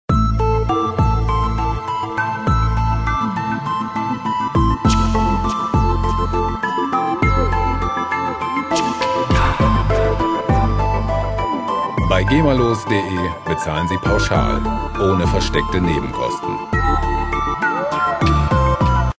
gemafreie Chillout Loops
Musikstil: Pop
Tempo: 101 bpm